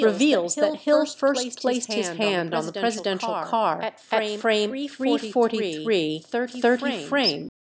echo_0.3.wav